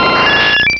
Cri de Roucarnage dans Pokémon Rubis et Saphir.